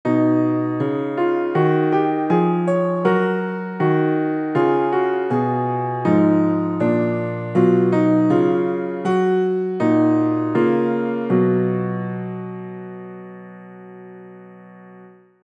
这是今天继续构造无穷升调的时候，为了衔接两个部分而写的 toolbars 两个小节，其实低音部分两节基本是一样的（偷懒嘛）。听起来和后面的还是不太接得上，索性当作副产品加工了一下，凑合当短信铃声，不过听起来有点悲，还是不合适啊…